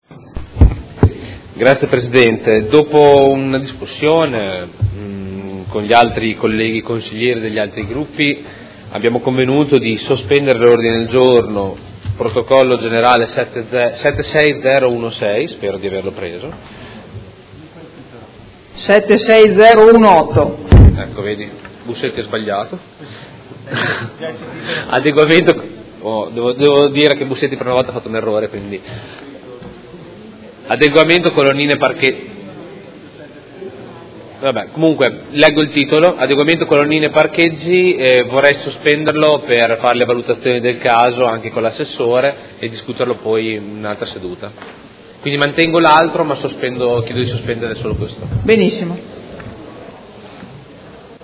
Seduta del 18/05/2017. Chiede sospensione Ordine del Giorno presentato dal Gruppo Movimento 5 Stelle avente per oggetto: Adeguamento colonnine parcheggi